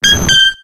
Cries
CLEFABLE.ogg